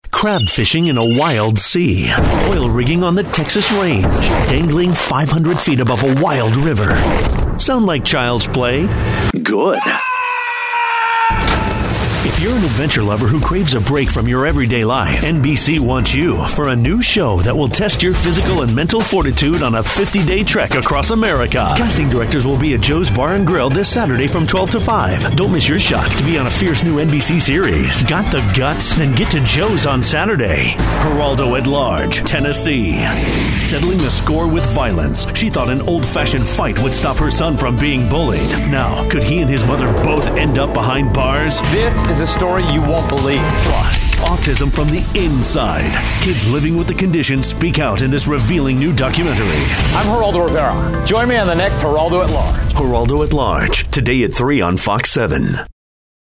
new york : voiceover : commercial : men